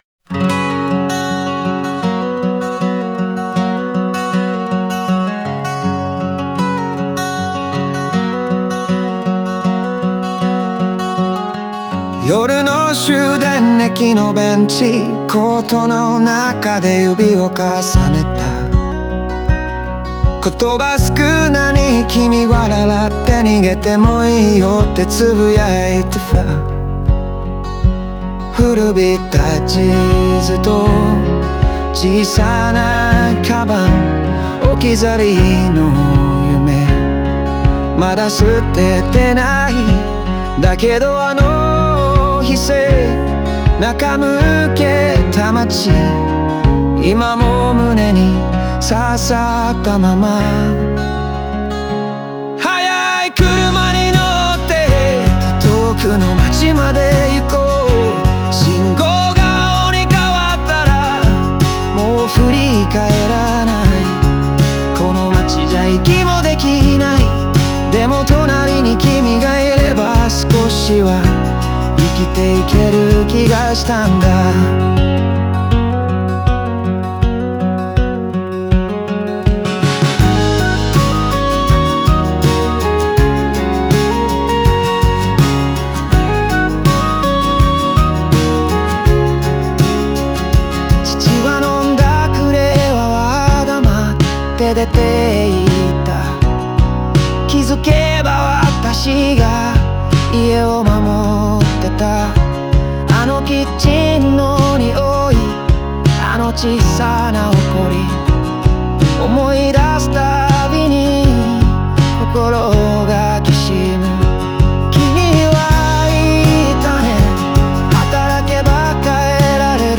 語りかけるような歌声とアコースティックな音色が、淡々とした現実のなかにある切実な感情を際立たせています。